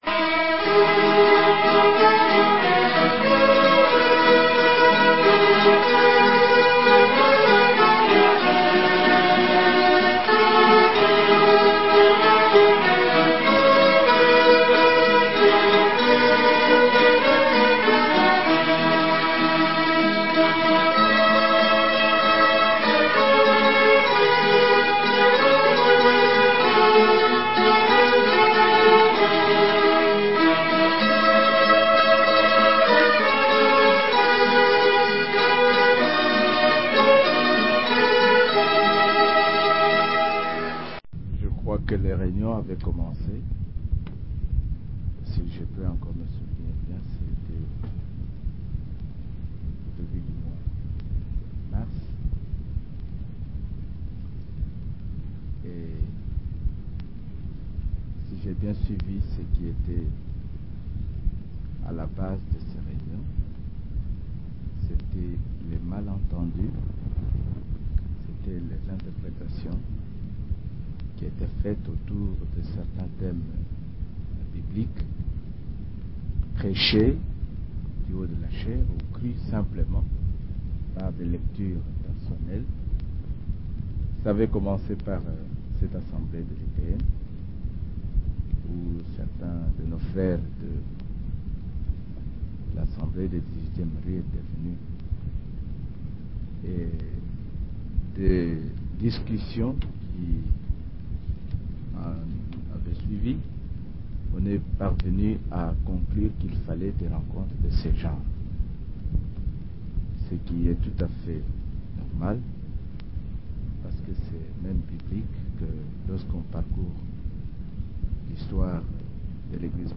Prédications
Réunion des serviteurs